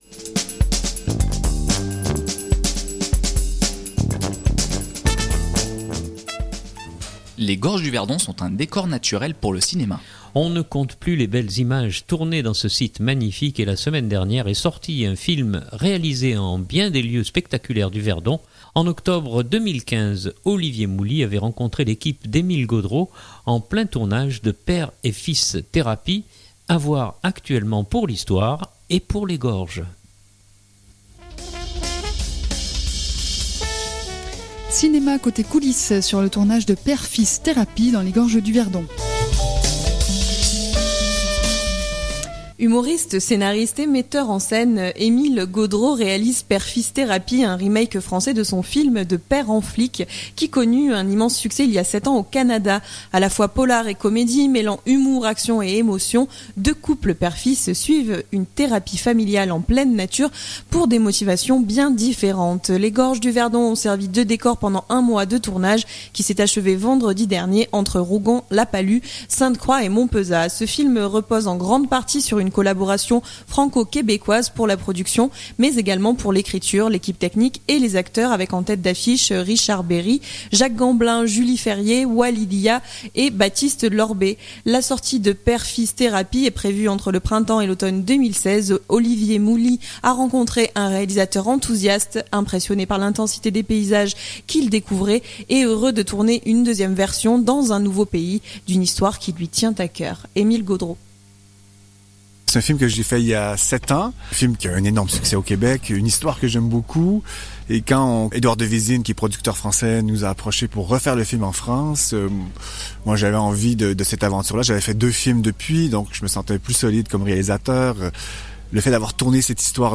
Cinéma côté coulisses sur le tournage de Père Fils Thérapie dans les gorges du Verdon (Rediffusion)